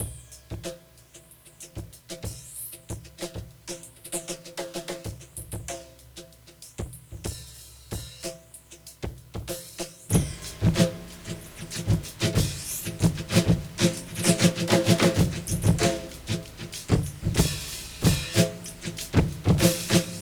Submerge 095bpm